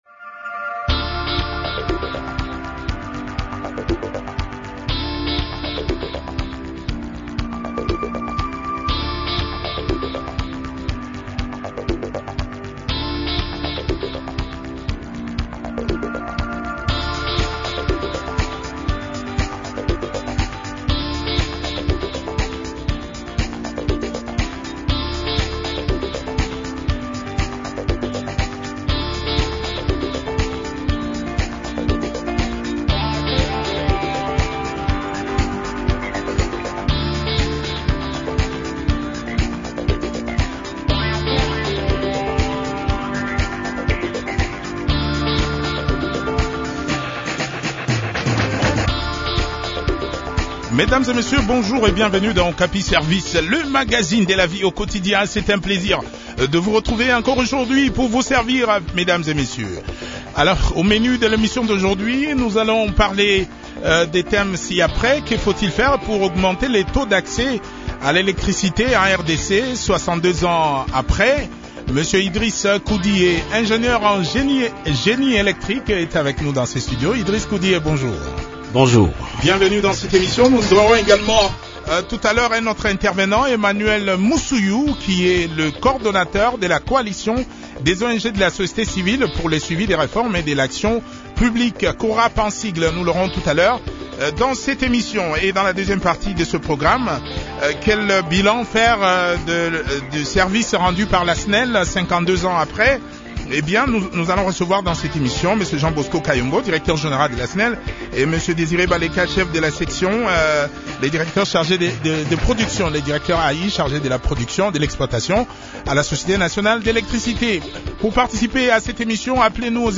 ingénieur en génie électrique et expert en énergies renouvelables.